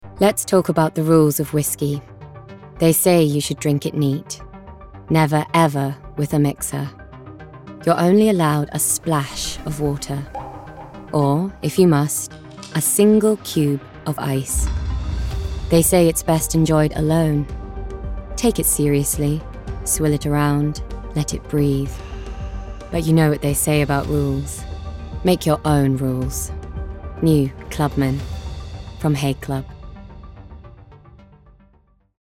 Voice Reel
Haig Club - RP, Assured, Smooth, Cool